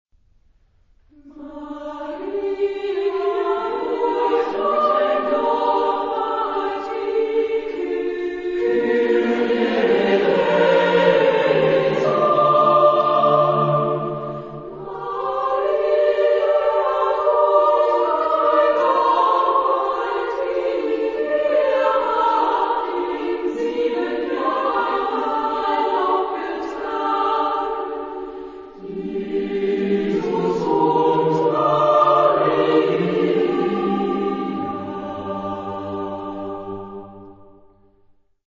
Genre-Stil-Form: geistlich ; Lied ; weltlich ; Weihnachtslied ; traditionell
Chorgattung: SSATB  (5 gemischter Chor Stimmen )
Tonart(en): B-dur